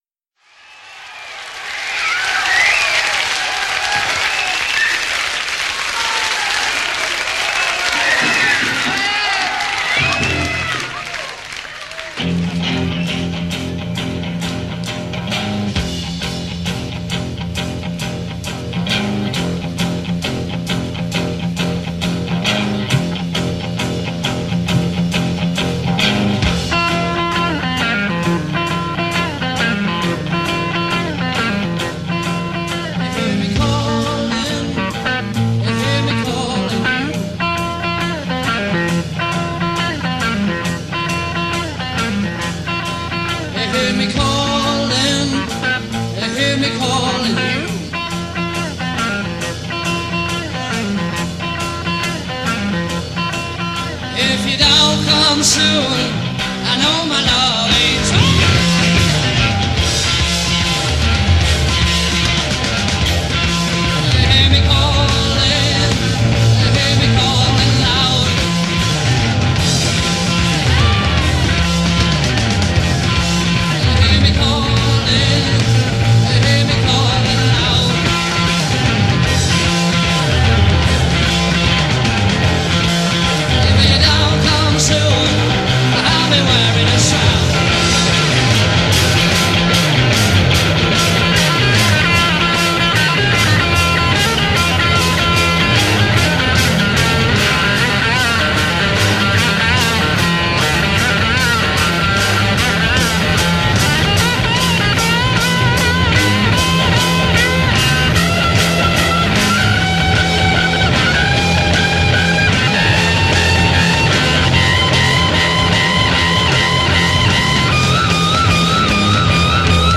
in concert from Golders Green Hippodrome, London
Glam/Metal
impossibly high voice
Glam with a Metal ring around it.